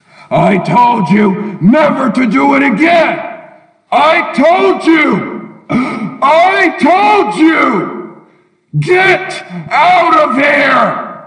Another one of my voice samples made using Audacity.